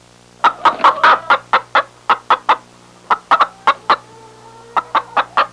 Hen Sound Effect
Download a high-quality hen sound effect.
hen-2.mp3